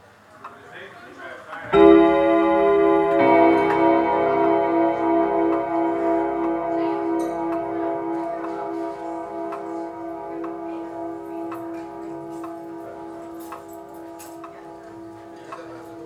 old_clock_ding_dong
beating bing-bong chime clock cymbal deep ding dong sound effect free sound royalty free Sound Effects